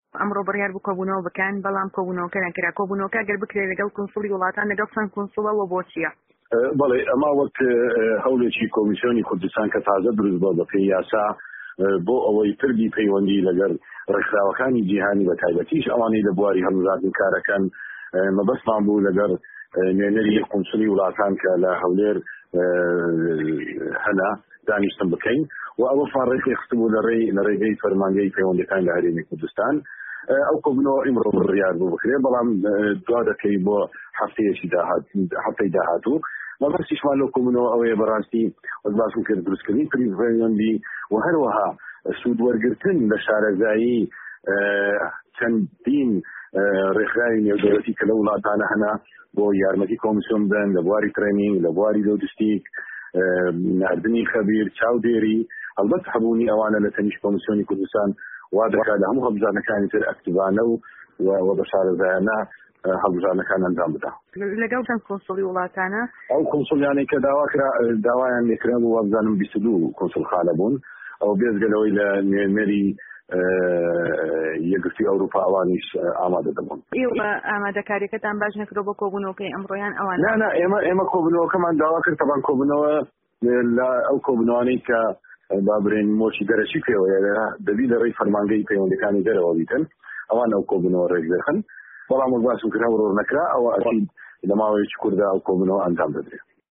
وتووێژ لەگەڵ هەندرێن محەمەد